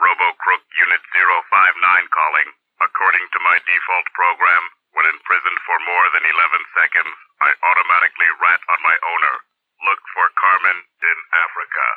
Crook Jail Call